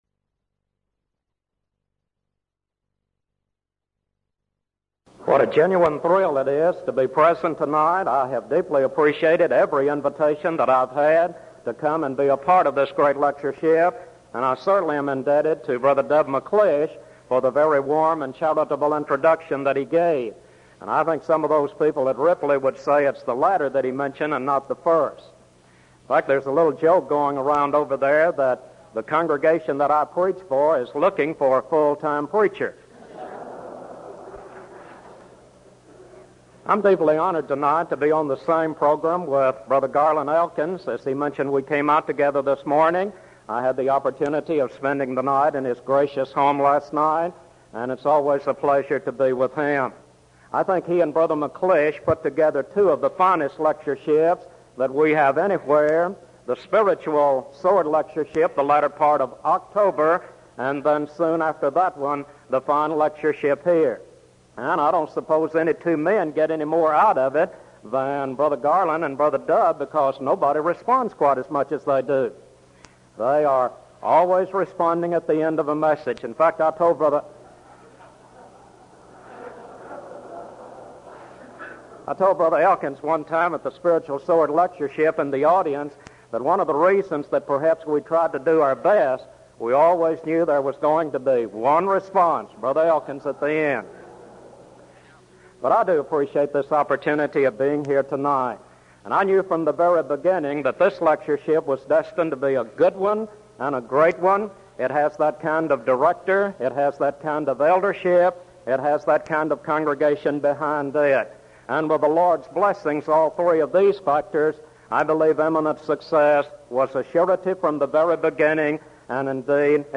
Event: 1984 Denton Lectures Theme/Title: Studies in the Book of Revelation
lecture